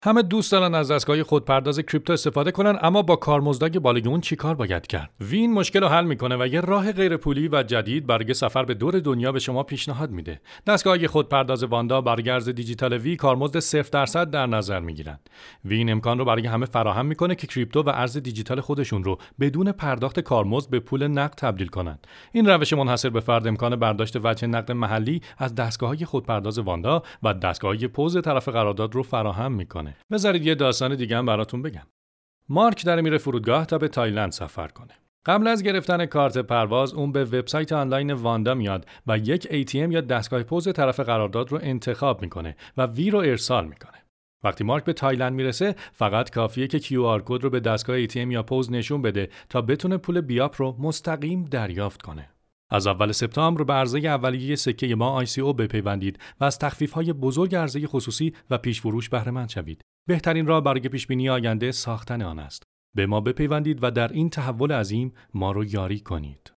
Male
Adult